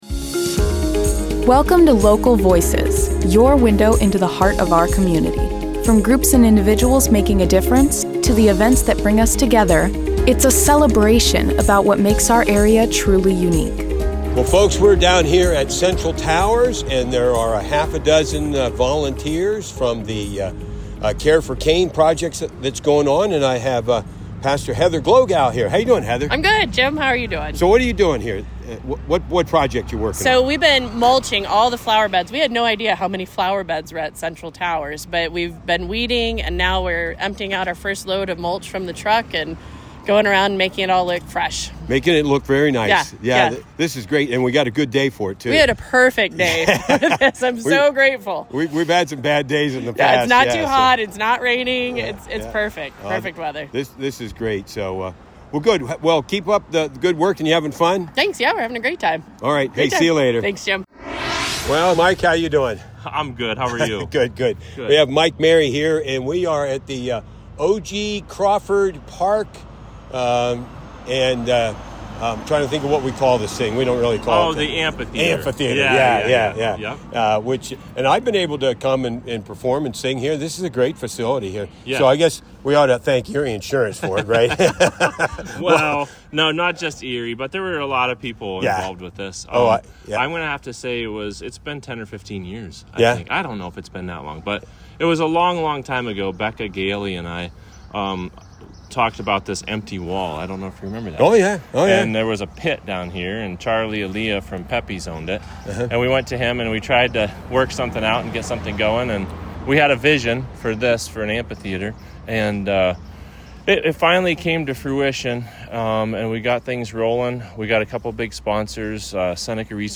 INTERVIEWS-KAREforKane 2025.mp3